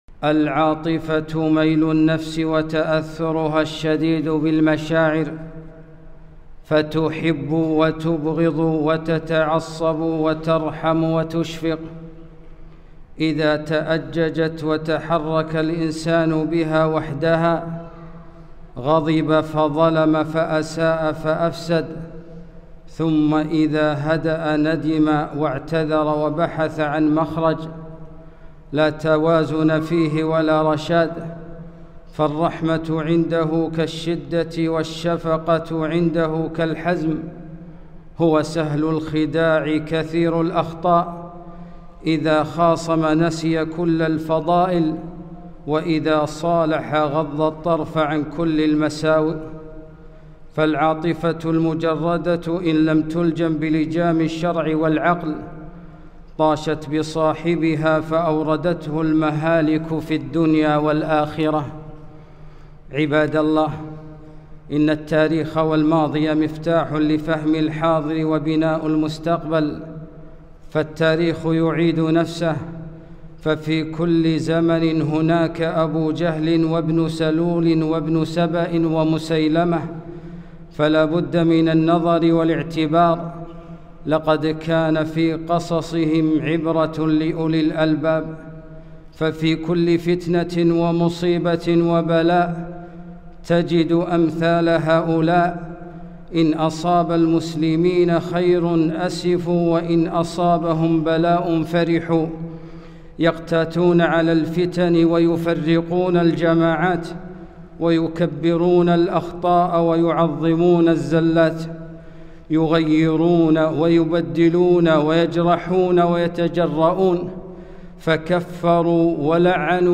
خطبة - العاطفة العاصفة